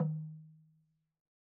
Percussion
LogDrumHi_MedM_v2_rr1_Sum.wav